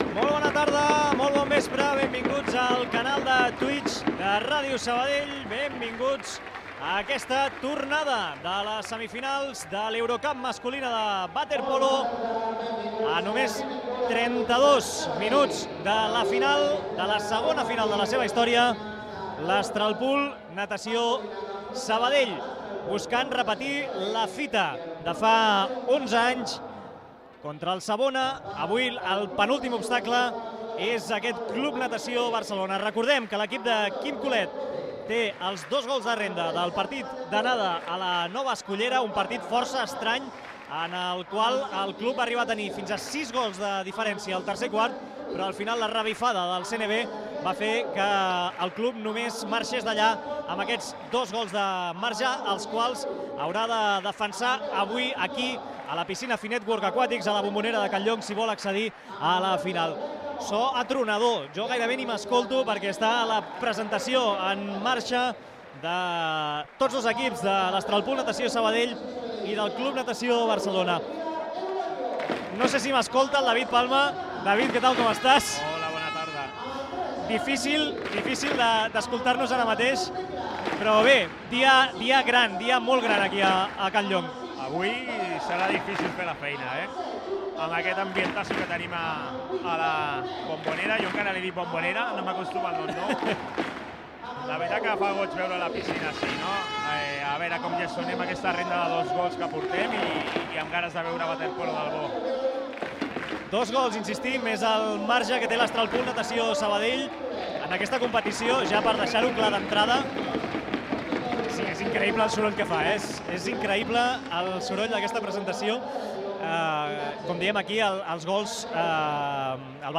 Transmissió de la semifinal de l’Euro Cup de waterpolo entre Astralpool Natació Sabadell i Club Natació Barcelona. Inici de la transmissió: estat de la competició, equips, primers minuts del primer quart.
Esportiu
Transmissió feta per Internet al canal de Twitch de Ràdio Sabadell.